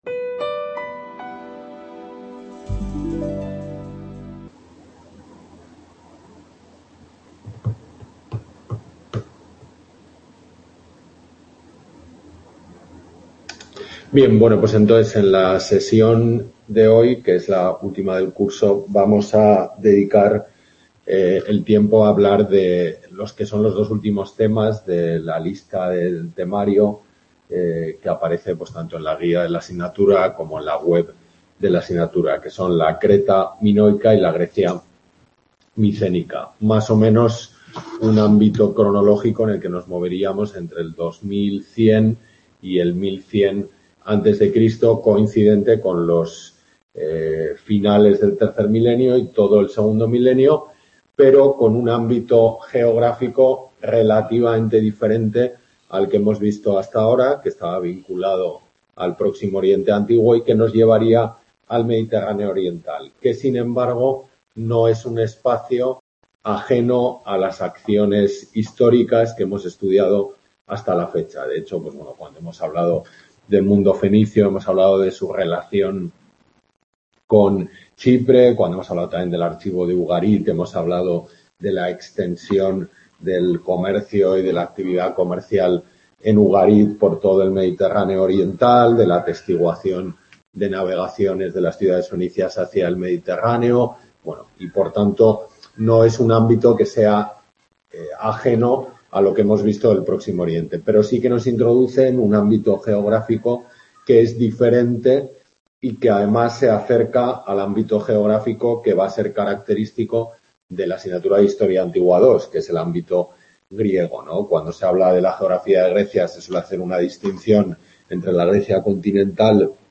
Tutoría de Historia Antigua I